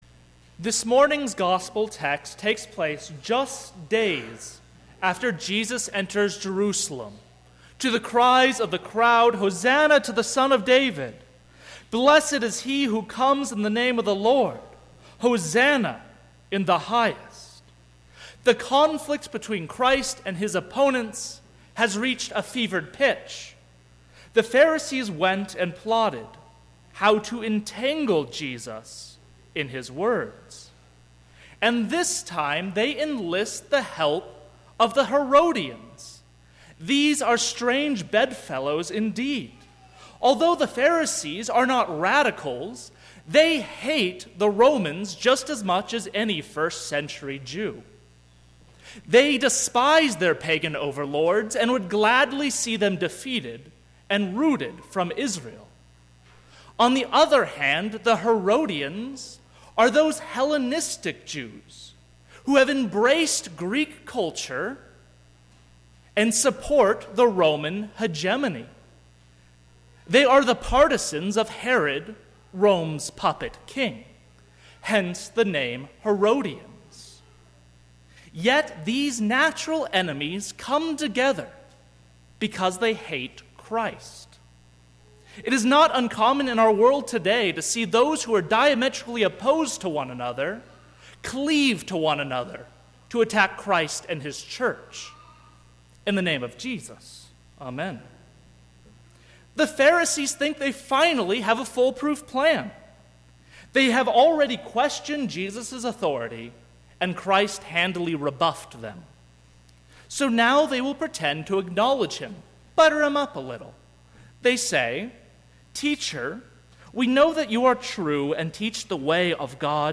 Sermon - 11/19/2017 - Wheat Ridge Lutheran Church, Wheat Ridge, Colorado
Twenty-Third Sunday after Trinity